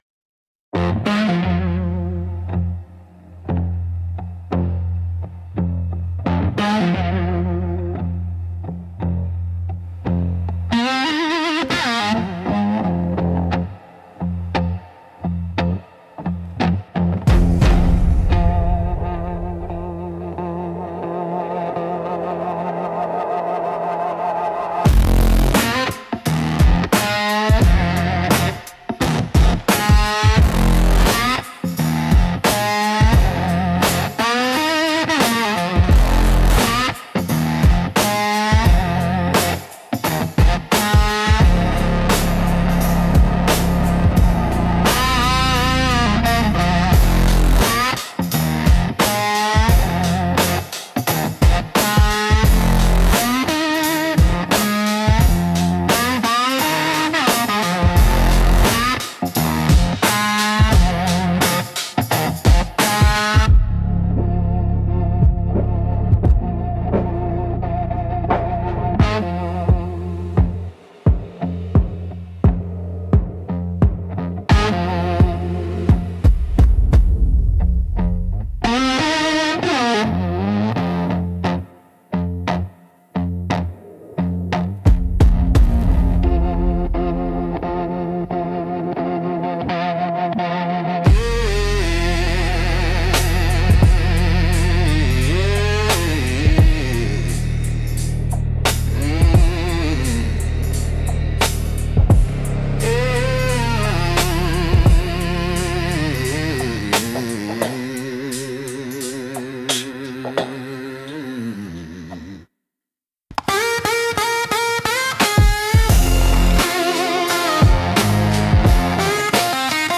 Instrumental - Blackwater Firewall - 2.40